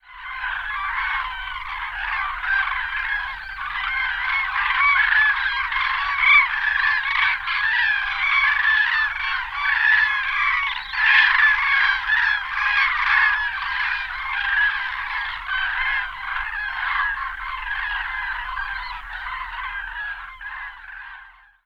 Kranich
Hören Sie sich hier die Stimme des Kranichs an: Kranich Rufe eines abfliegenden Trupps
887-kranich_rufe_eines_abfliegenden_trupps-soundarchiv.com_.mp3